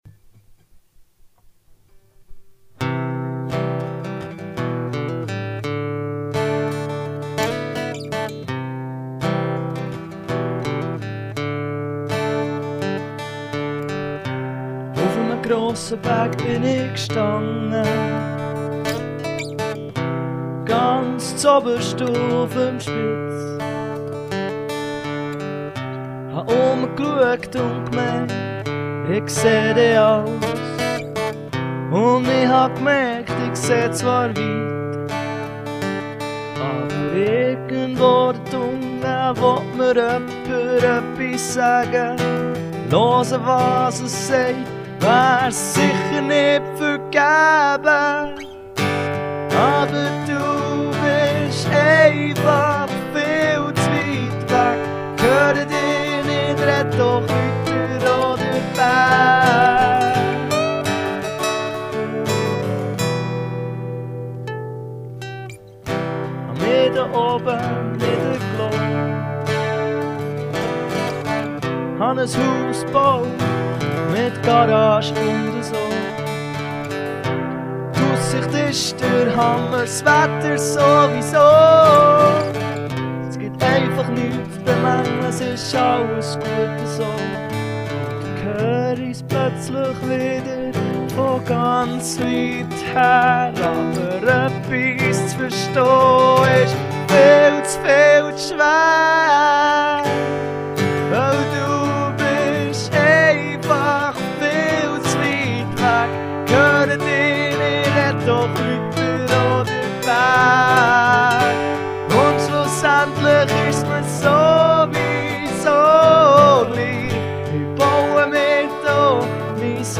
It's two guitar and two vocal tracks.
Something about the melody sounds familiar. Maybe it's the chorus.
but seriously, not bad, the first guitar part is kind of bleh but the solo work is good. I could groove to this